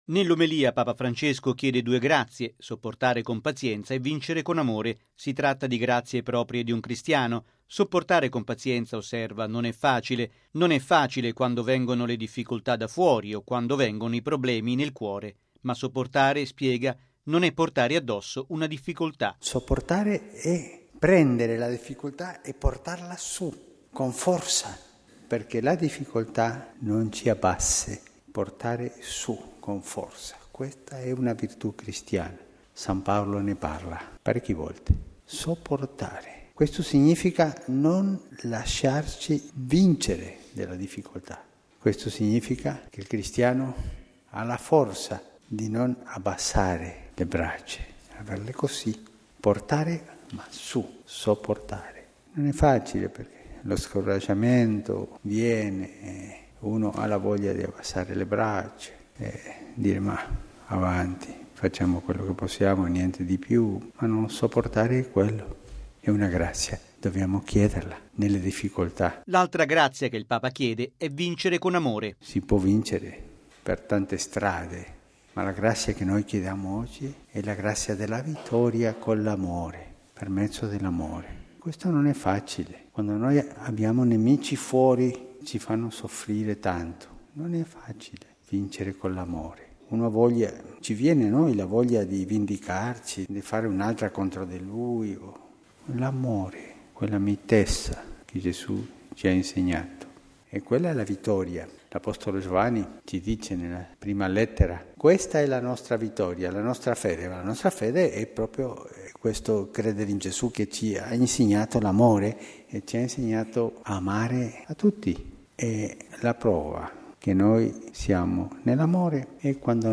◊   “Sopportare con pazienza e vincere con amore le oppressioni esterne ed interne”: è la preghiera elevata da Papa Francesco durante la Messa Santa Marta nella memoria di Maria Ausiliatrice.